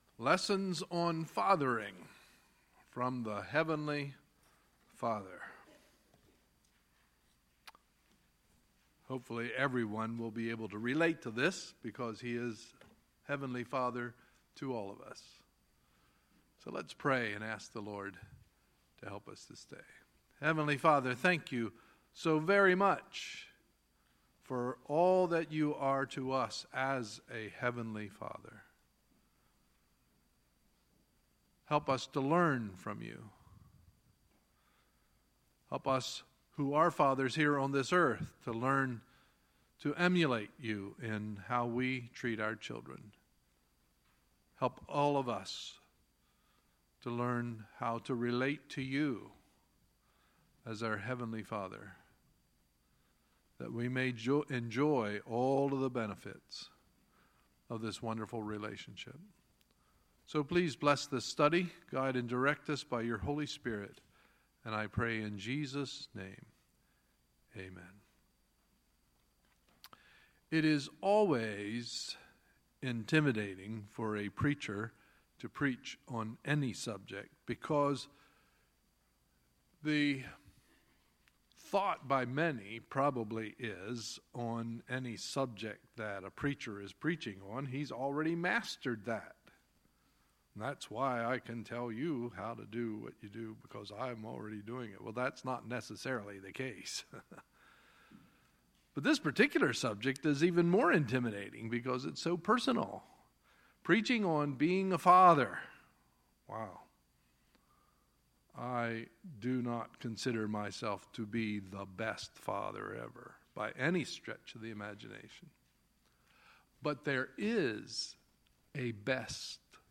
Sunday, June 18, 2017 – Sunday Morning Service
Sermons